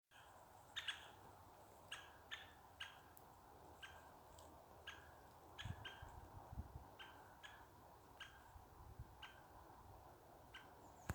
White-backed Woodpecker, Dendrocopos leucotos